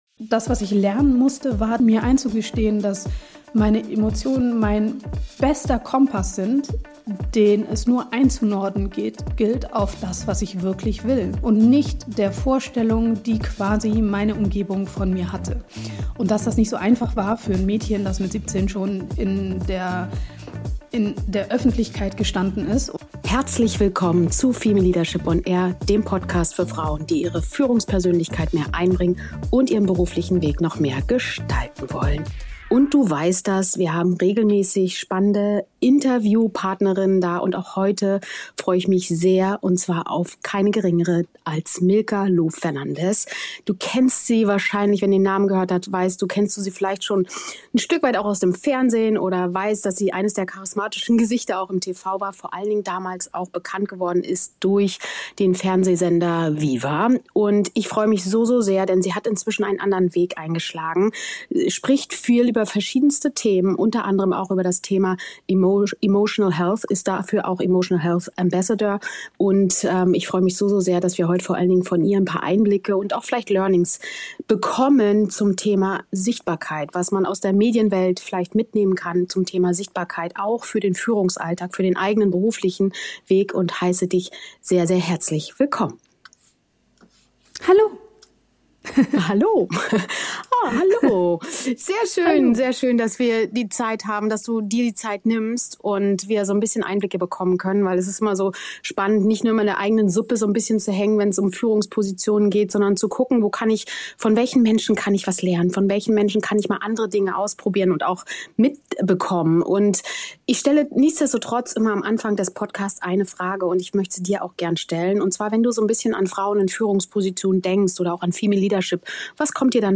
In dieser kraftvollen und zugleich ehrlichen Folge spreche ich mit Milka Loff Fernandes - Moderatorin, Autorin, Emotional Health Ambassador über Sichtbarkeitsstrategien.